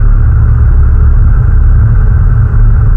AmbDroneO.wav